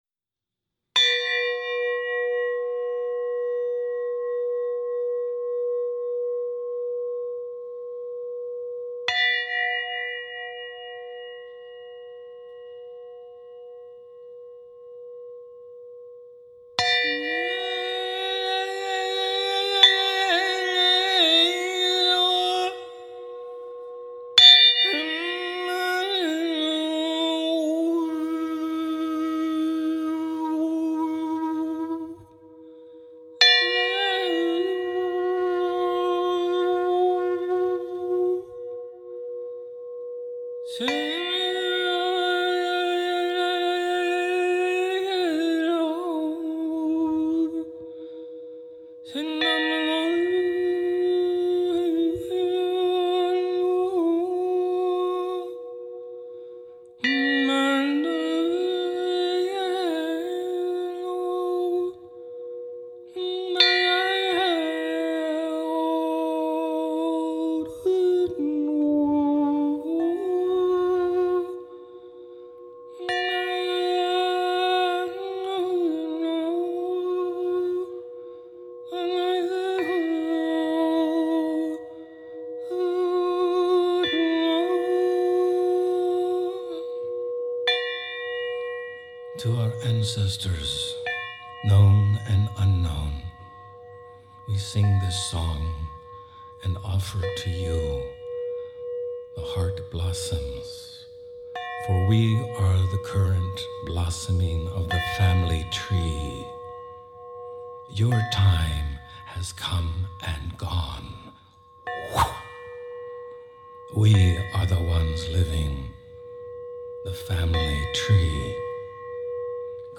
TK-Ancestral-Healing-Sound-Meditation.mp3